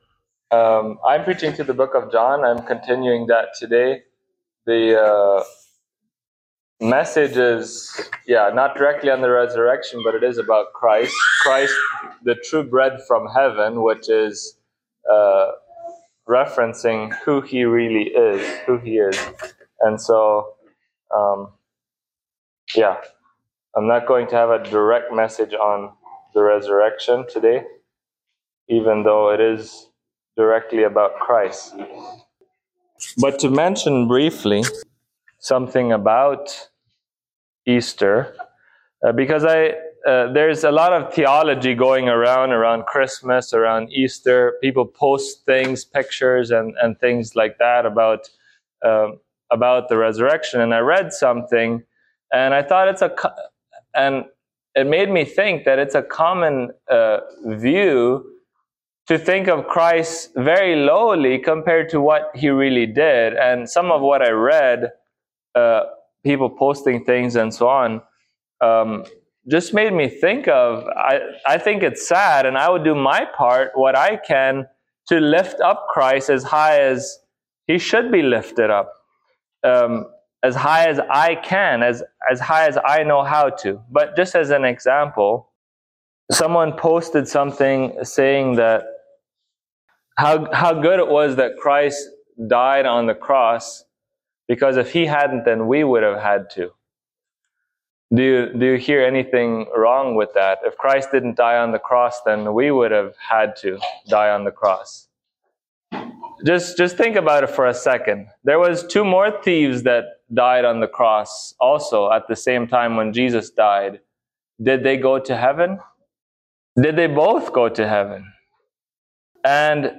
John Passage: John 6:28-35 Service Type: Sunday Morning Topics